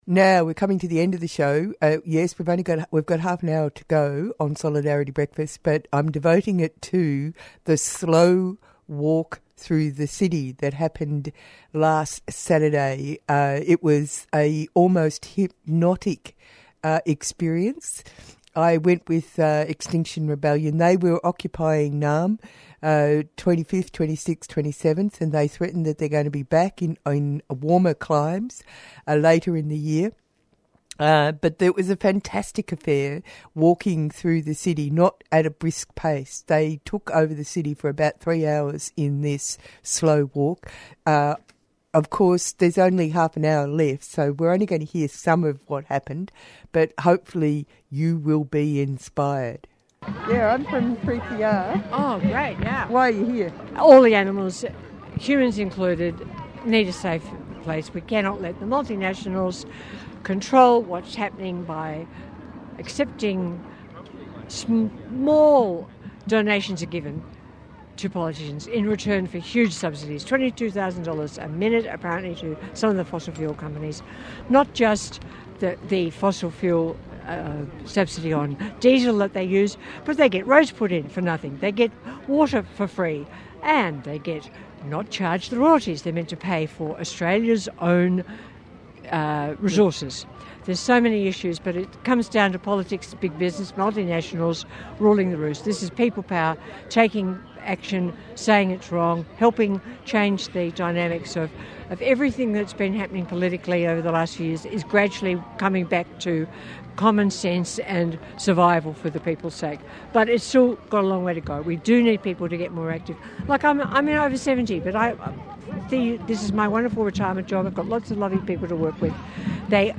We go to the steps of Parliament for this report.